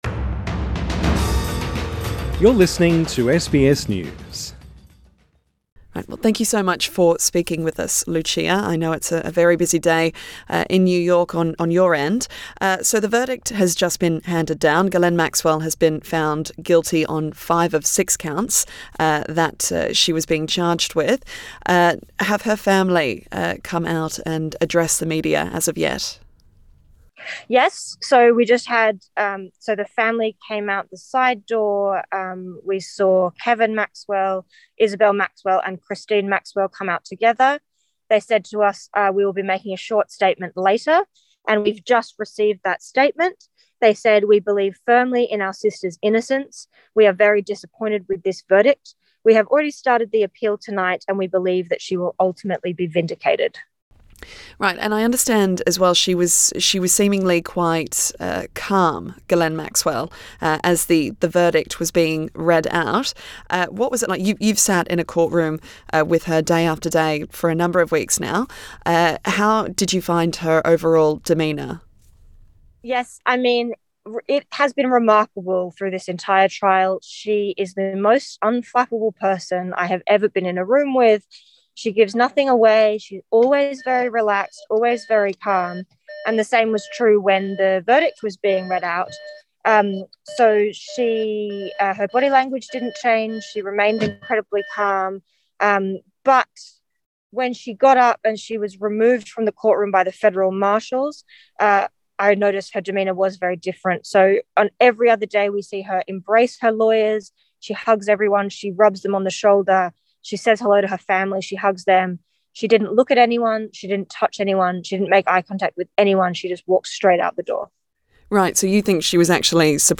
Interview with reporter who covered Ghislaine Maxwell case